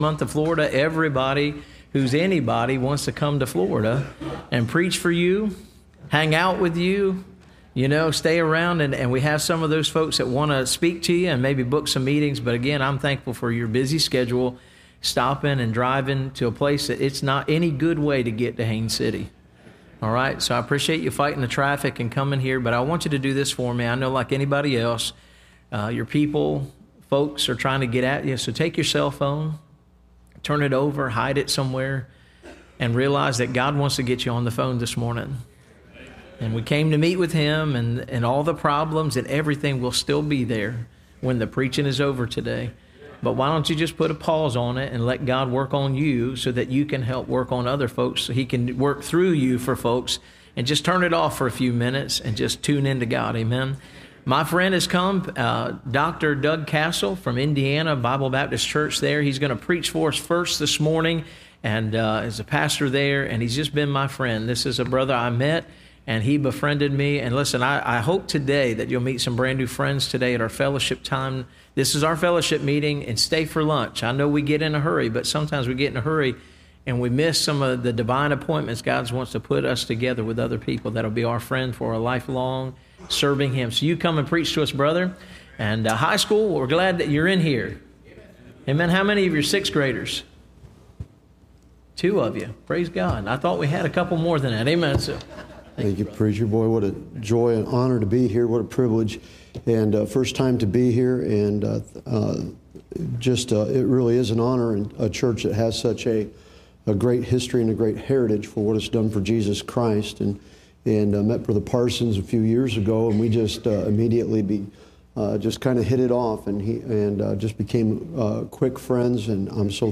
Service Type: Bible Conference